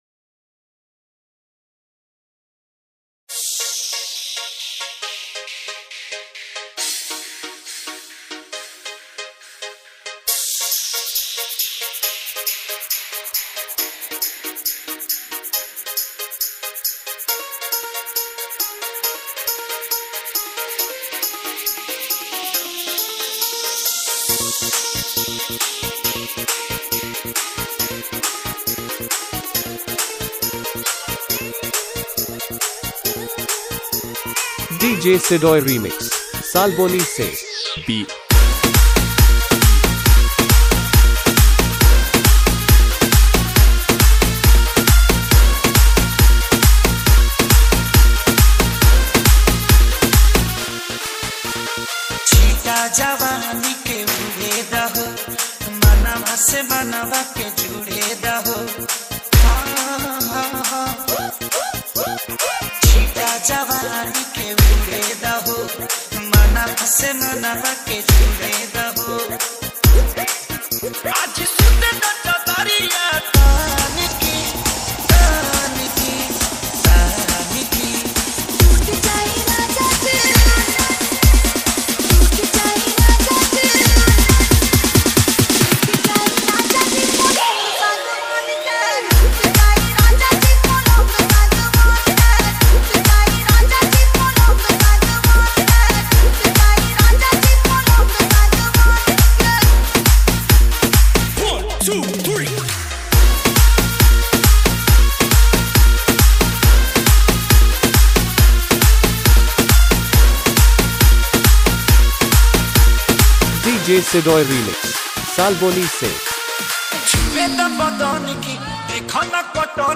Bhojpuri Sad DJ Remix